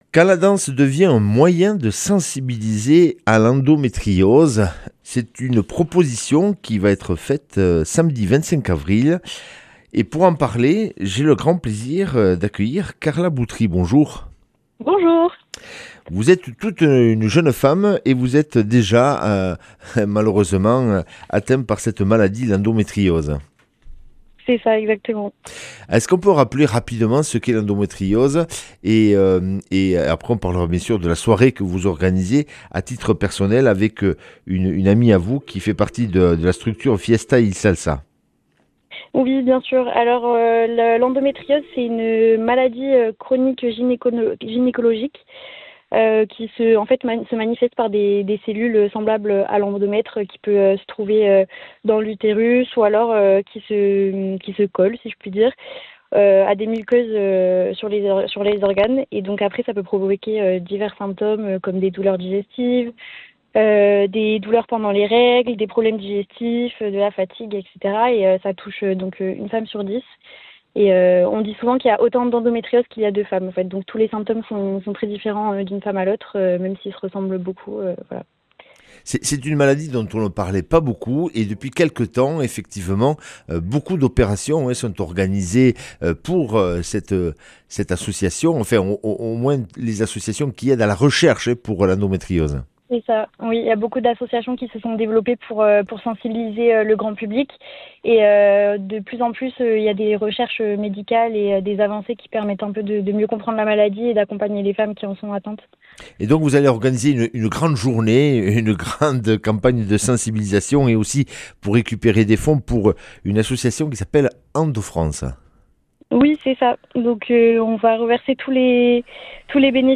lundi 20 avril 2026 Interview et reportage Durée 10 min